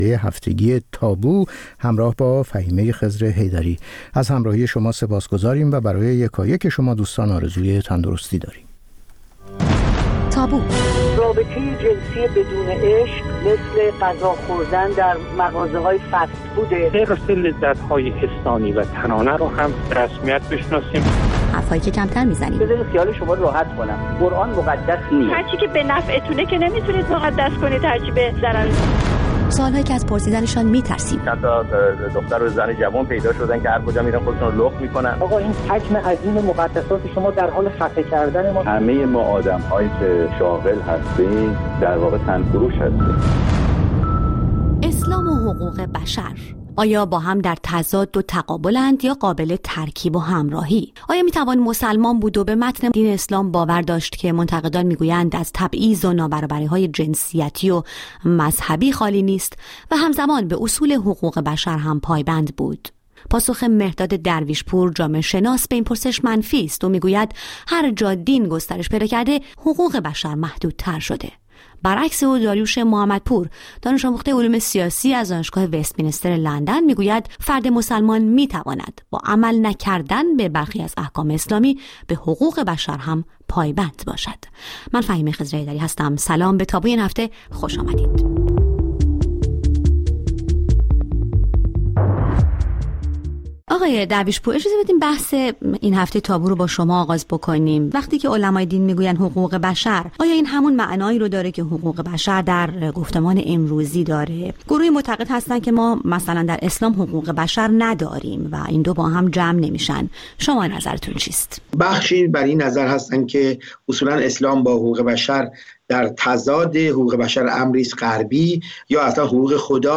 با دو‌ مهمانِ برنامه درباره‌ی موضوعاتی که اغلب کمتر درباره‌شان بحث و گفت‌وگو کرده‌ایم به مناظره می‌نشیند. موضوعاتی که کمتر از آن سخن می‌گوییم یا گاه حتی ممکن است از طرح کردن‌شان هراس داشته باشیم.